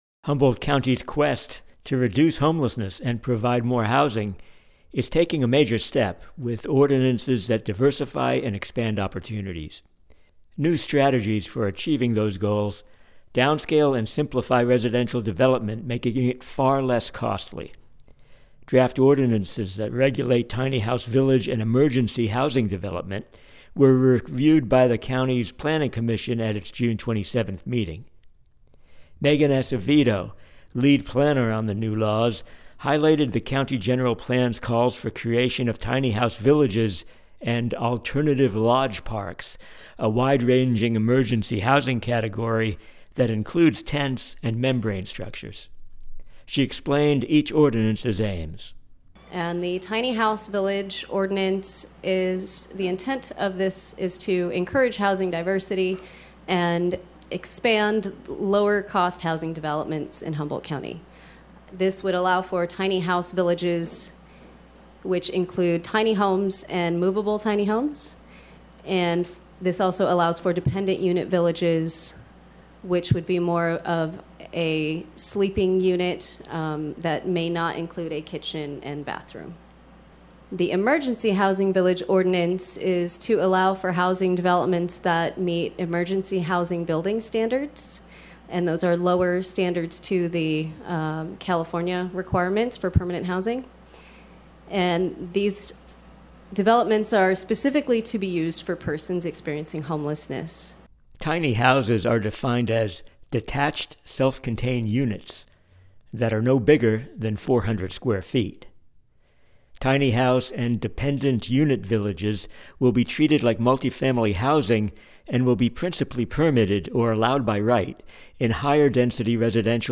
KMUD News